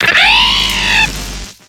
Cri de Luxio dans Pokémon X et Y.